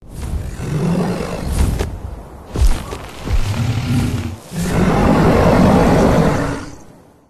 🐉 Dragon sound redesign 📷 sound effects free download